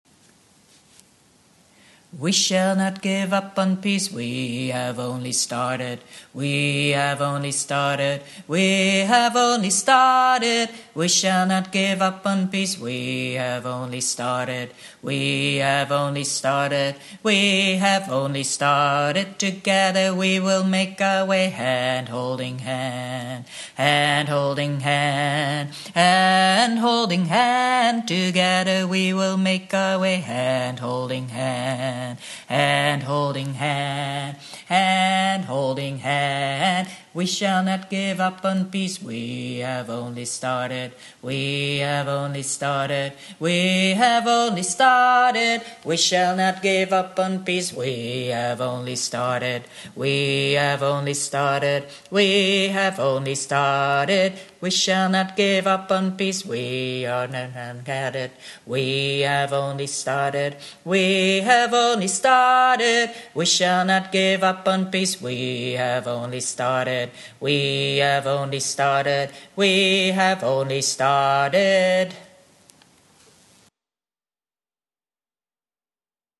We-Shall-Not-Give-Up-On-Peace-Bass.mp3